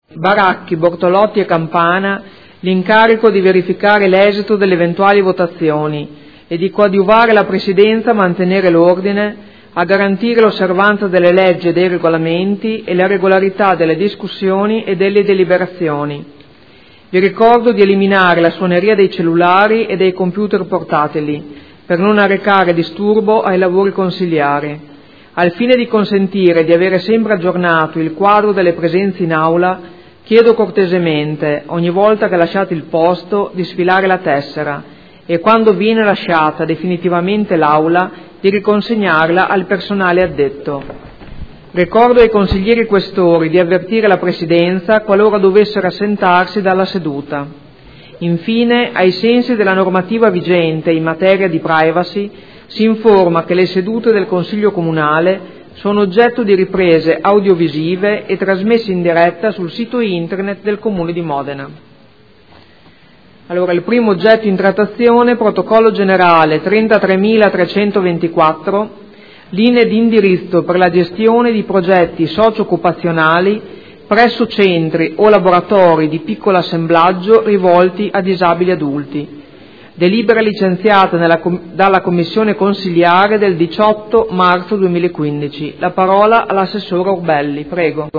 Presidente — Sito Audio Consiglio Comunale
Apertura del Consiglio Comunale